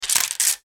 Взяли счеты в руку звук